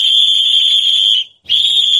Free SFX sound effect: Soccer Kick.
Soccer Kick
362_soccer_kick.mp3